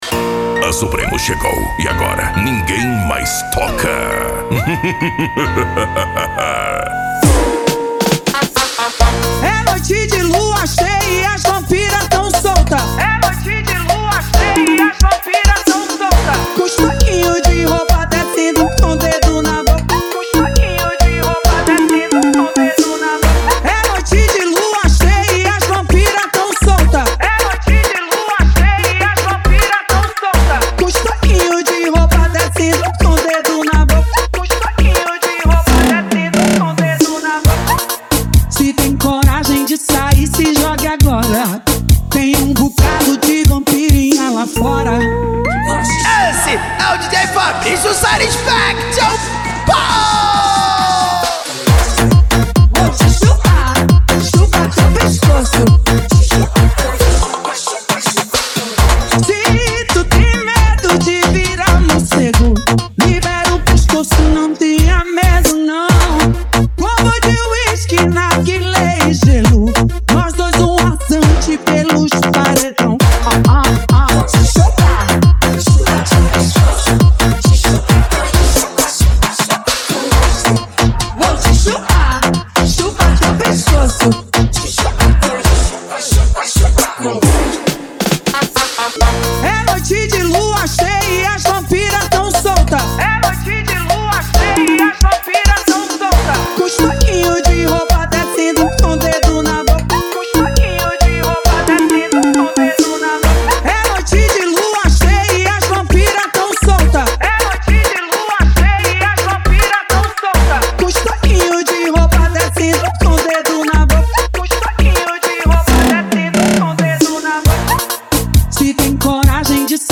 Arrocha
Funk